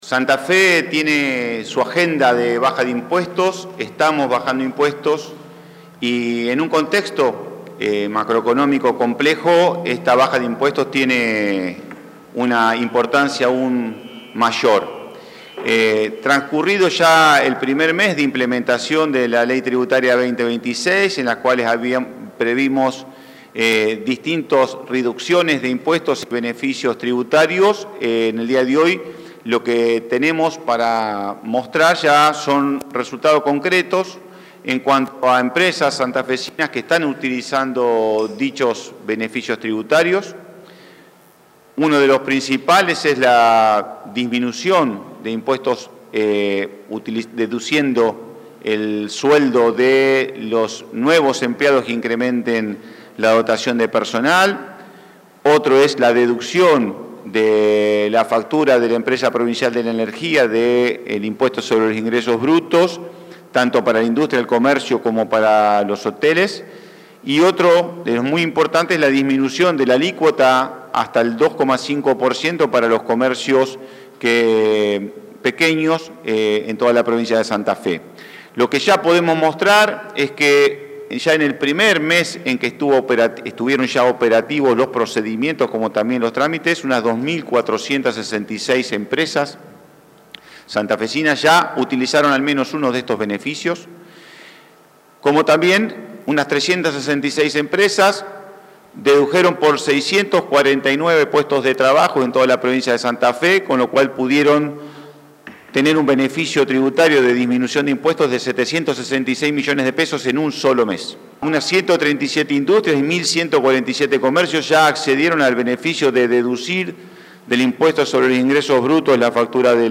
Durante la conferencia también se detalló la cantidad de empresas que solicitaron beneficios vinculados a la ampliación de plantillas laborales, así como los pedidos de comercios minoristas para acceder a la reducción de alícuotas prevista para el sector.
Presentación de los ministros Olivares, Báscolo y Puccini